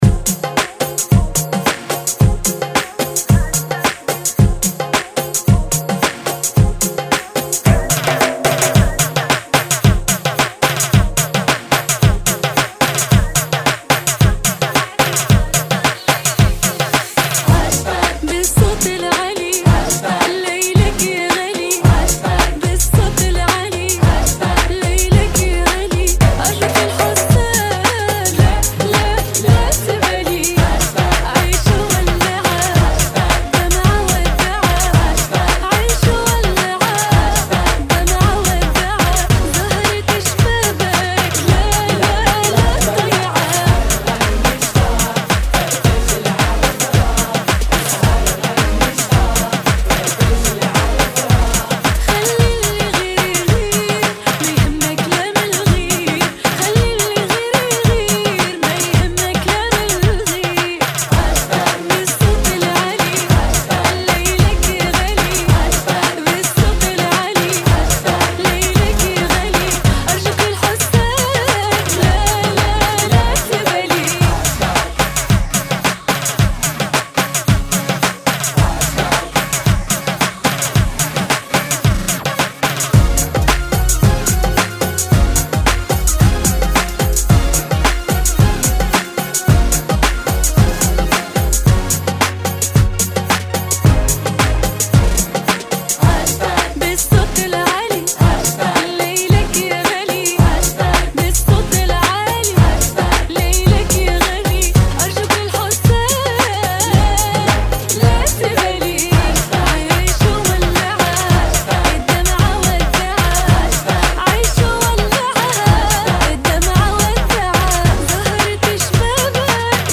Funky [ 110 Bpm